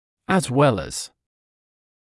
[əz wel əz][эз уэл эз]так же как, а также